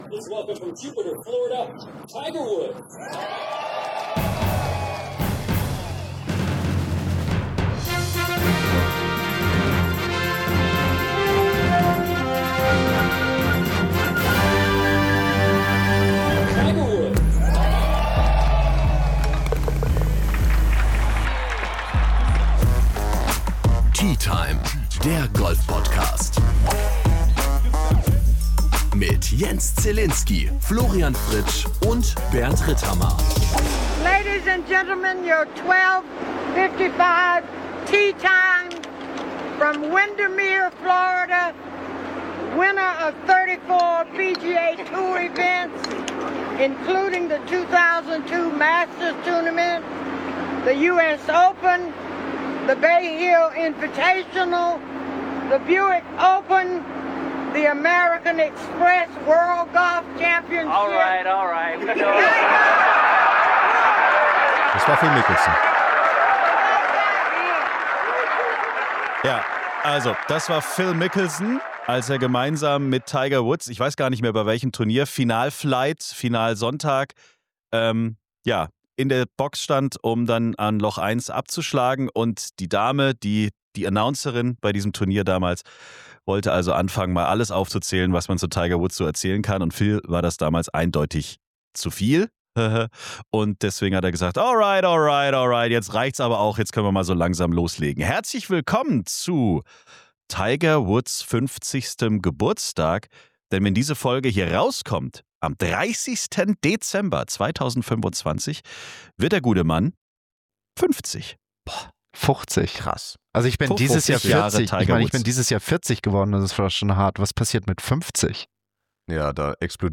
Mit exklusiven O-Tönen